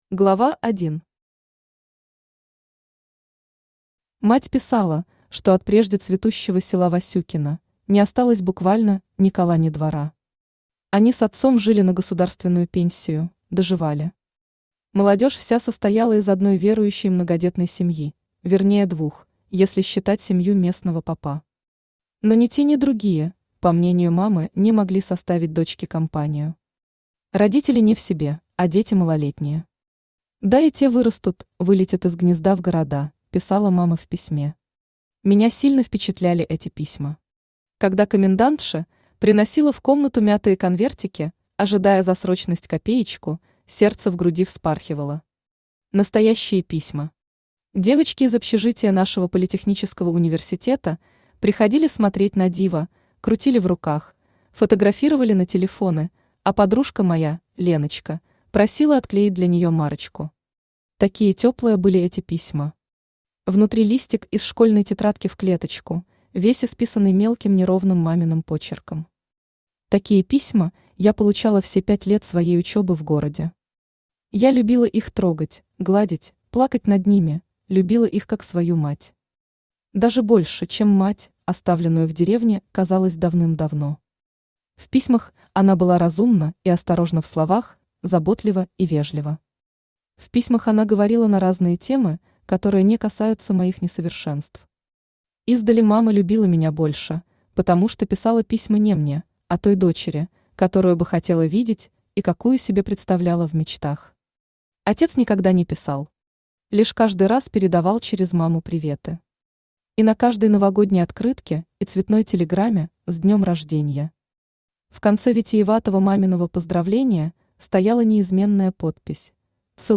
Аудиокнига Простые девичьи тайны | Библиотека аудиокниг
Aудиокнига Простые девичьи тайны Автор Юлия Валерьевна Шаманская Читает аудиокнигу Авточтец ЛитРес.